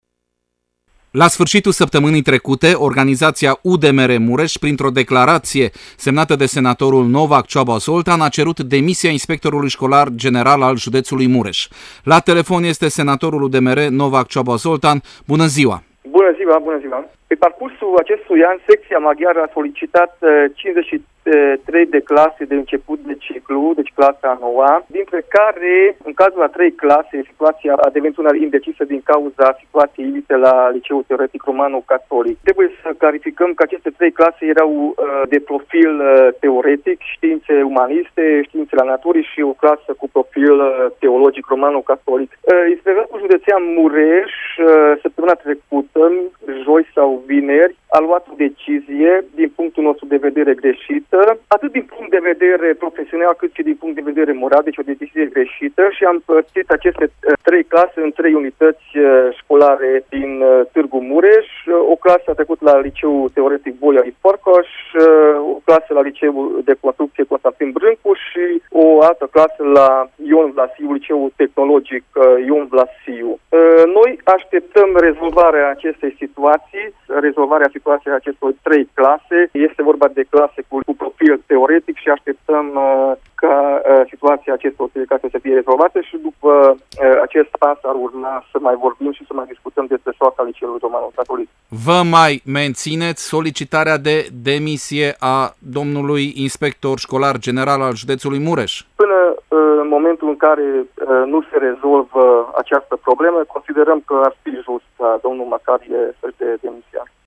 senator-novak.mp3